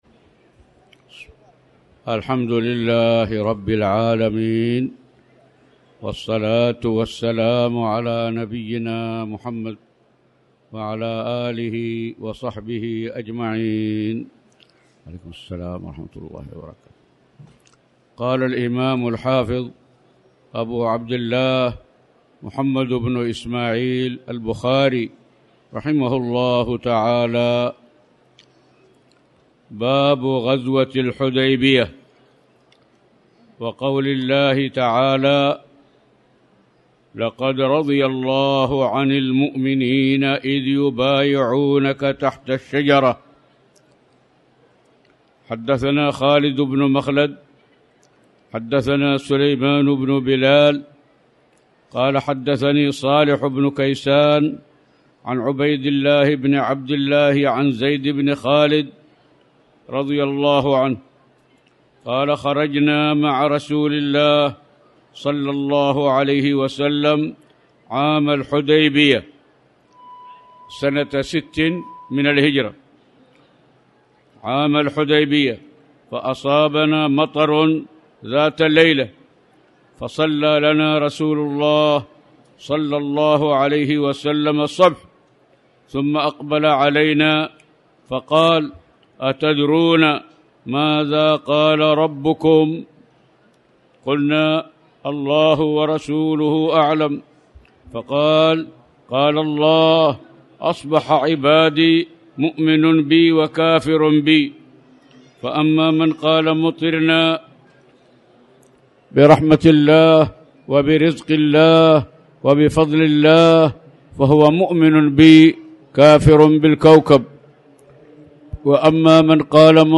تاريخ النشر ٢٣ شعبان ١٤٣٩ هـ المكان: المسجد الحرام الشيخ